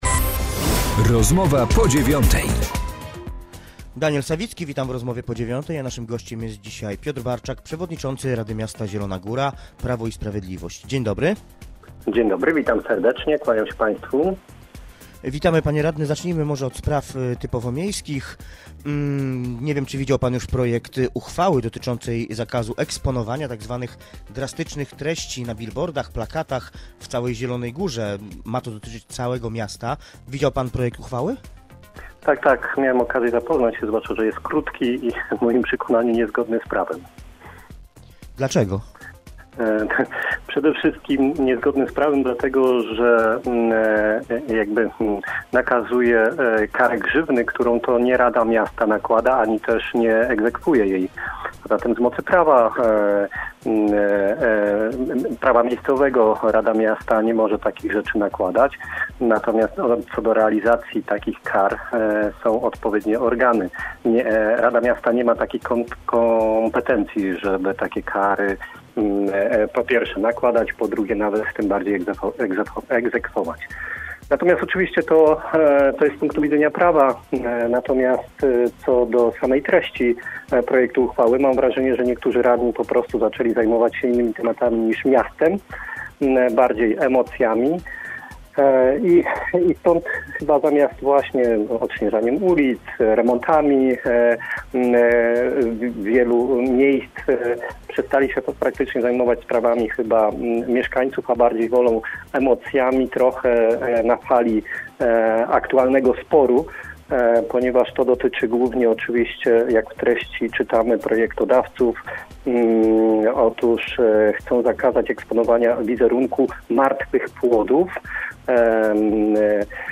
Z przewodniczącym rady miasta, radnym Prawa i Sprawiedliwości rozmawia